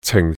Yue-cing4.mp3